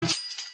KA CHING
Category: Sound FX   Right: Personal